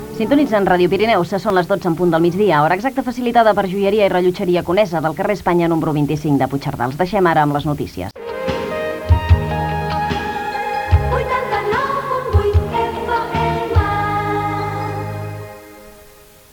Identificadció, hora i indicatiu de l'emissora
FM